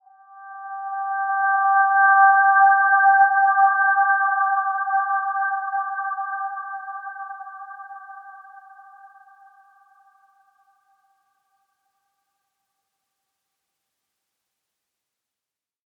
Dreamy-Fifths-G5-f.wav